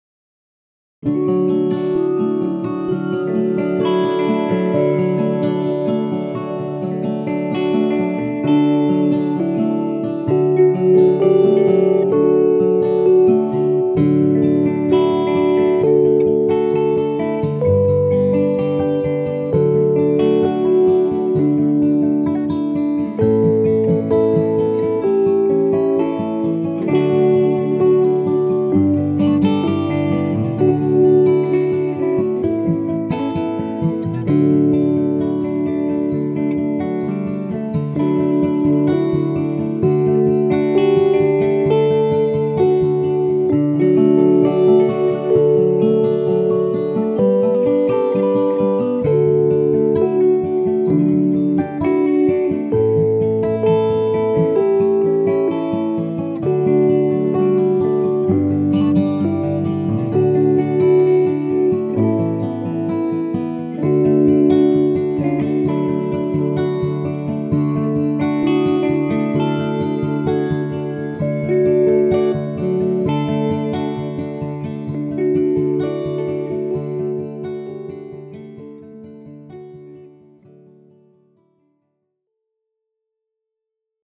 Latviešu tautas dziesma ar fonogrammu.